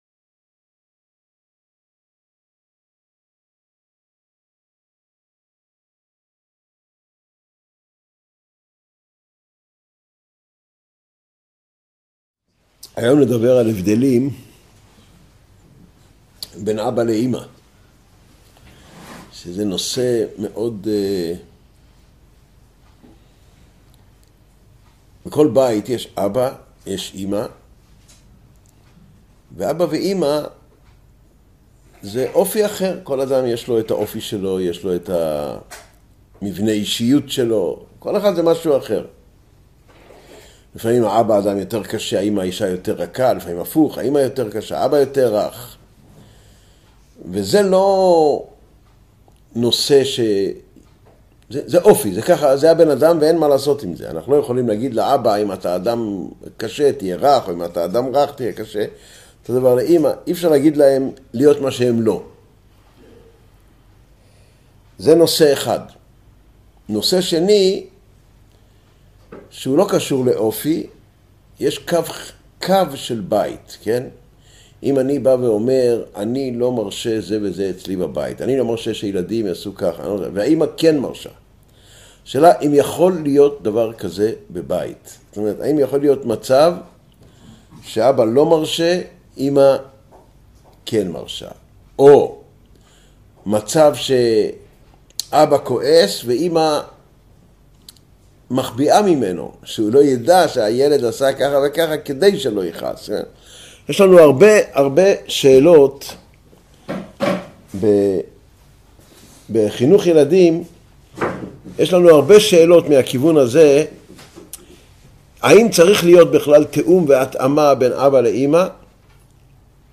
Воспитание детей Урок № 63 Папа и мама Содержание урока